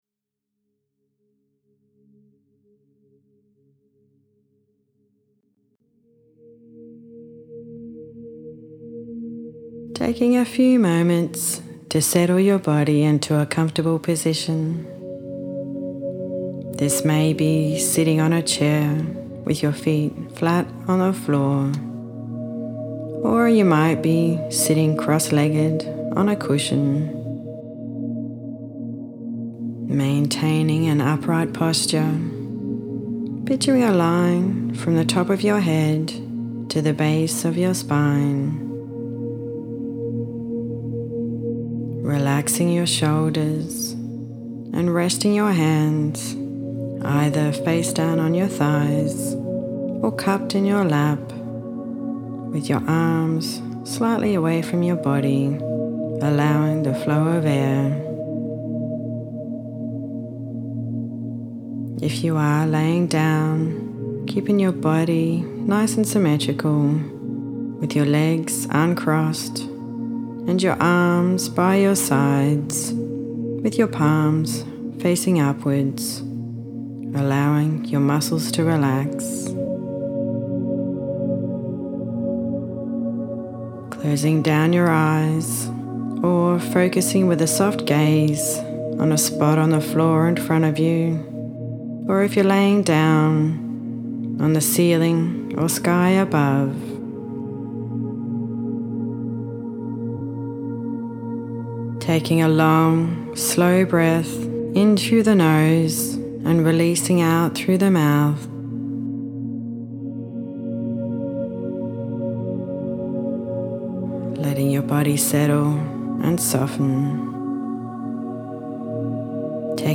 Listen to my Letting go of Thoughts Meditation